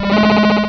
Cri d'Écrémeuh dans Pokémon Rubis et Saphir.